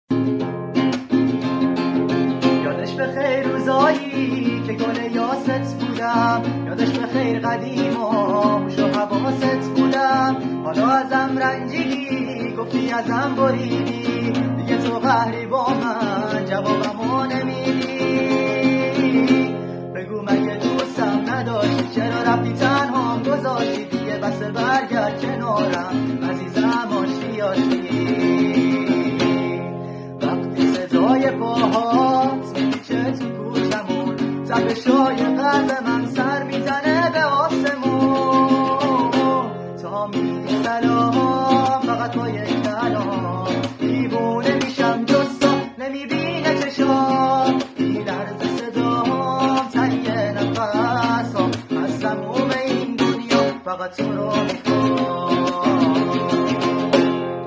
صدای پسر با گیتار